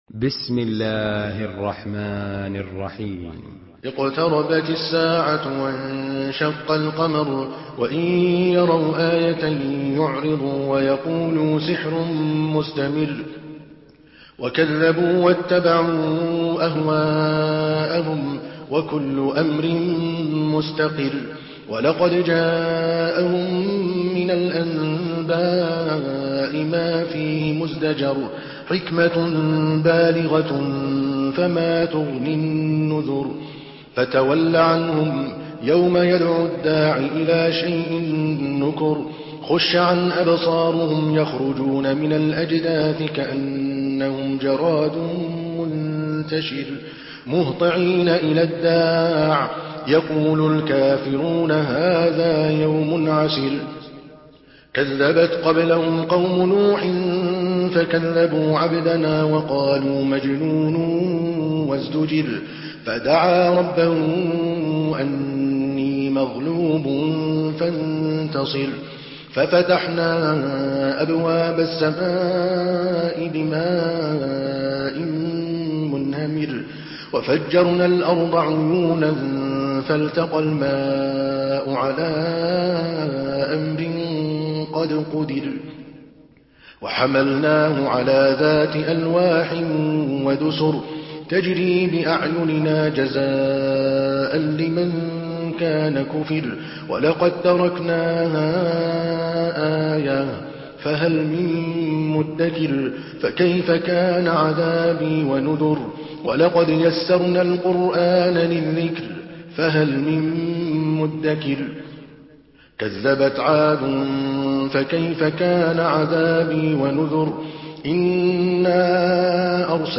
Surah Al-Qamar MP3 in the Voice of Adel Al Kalbani in Hafs Narration
Murattal Hafs An Asim